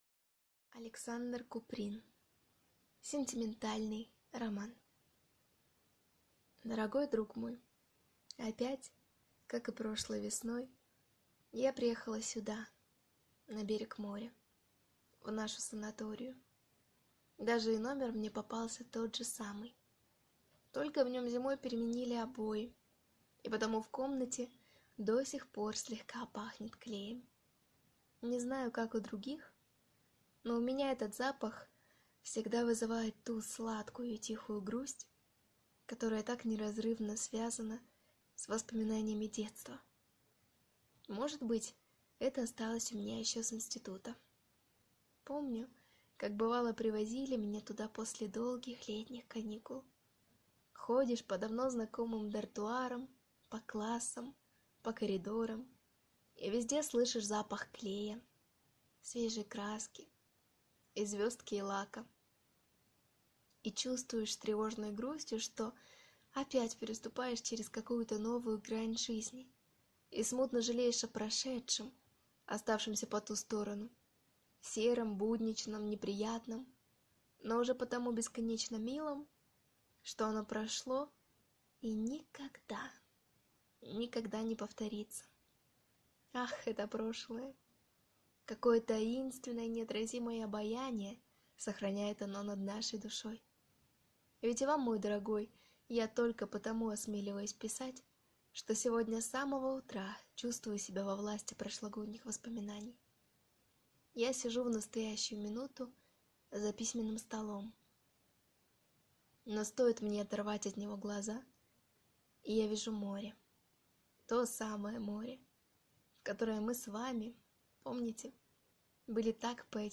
Аудиокнига Сентиментальный роман | Библиотека аудиокниг